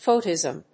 /ˈfəʊtɪzəm(英国英語)/